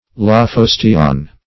Meaning of lophosteon. lophosteon synonyms, pronunciation, spelling and more from Free Dictionary.
Search Result for " lophosteon" : The Collaborative International Dictionary of English v.0.48: Lophosteon \Lo*phos"te*on\, n.; pl.